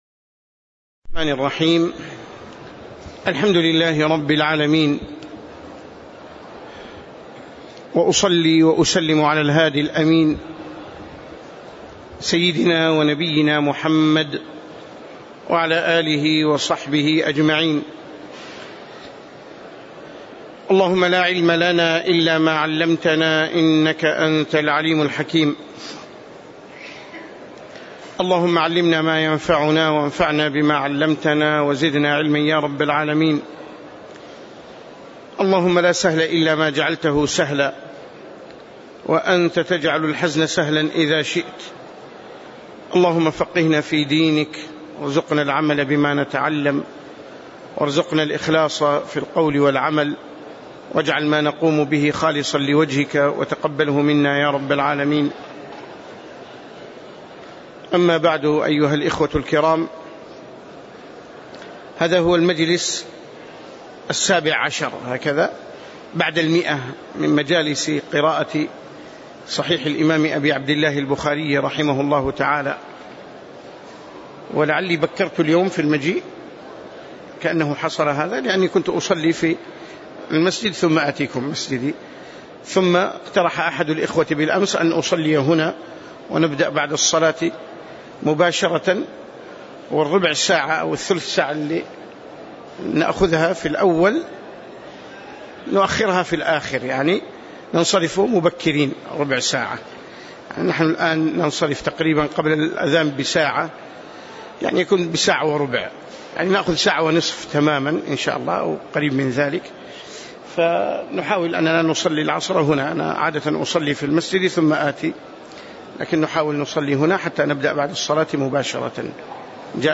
تاريخ النشر ١٣ شعبان ١٤٣٨ هـ المكان: المسجد النبوي الشيخ